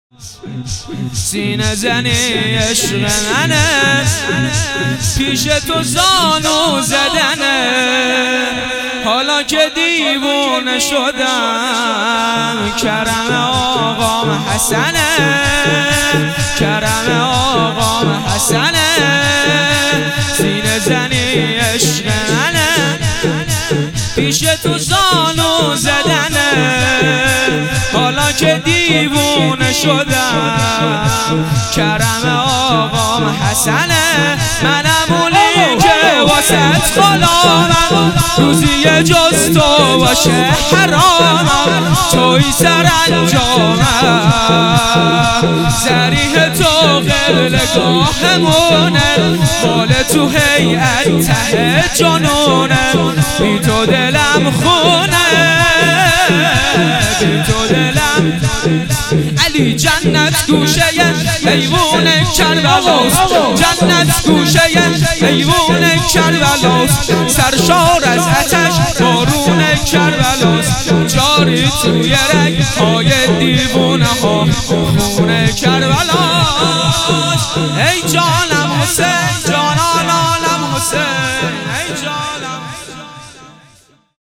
شور
سینه زنی عشق منه